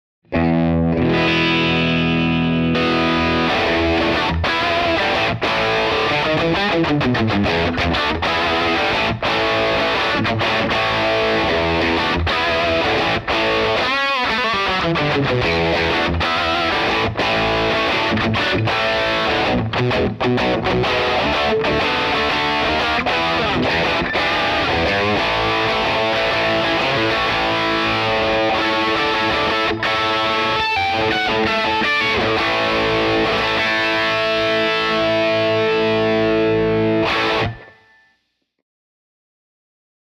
Treble Booster
Built to enhance the natural voice of your instrument and amplifier, it delivers everything from a subtle, glassy edge to full-bodied, saturated drive—without sacrificing articulation or touch response.
By focusing on the upper midrange and treble frequencies, the Brighton cuts through dense mixes, tightens up low end, and adds the kind of musical push that has defined some of the most iconic guitar tones in history.